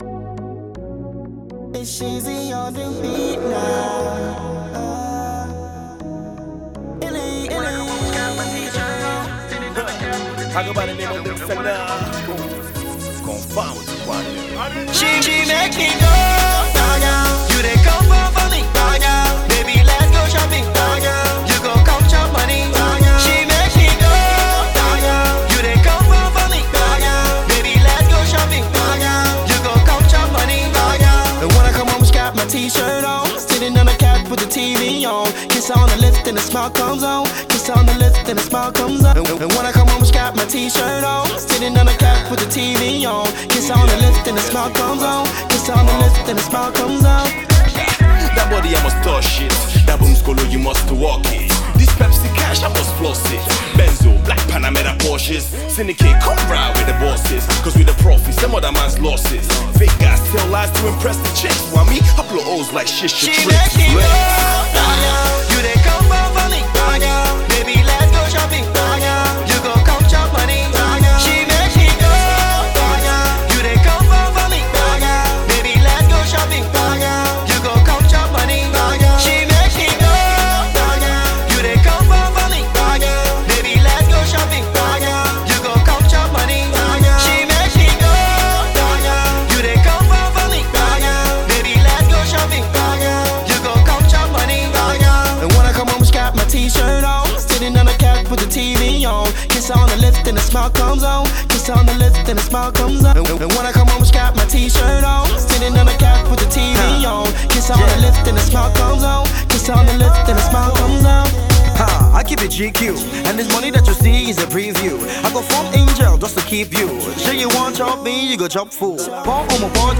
a fresh and original, new Afro Pop sound
laidback joint